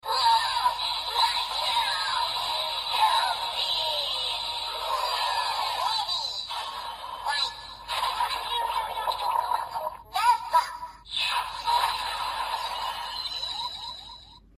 霸牛饱藏音效.MP3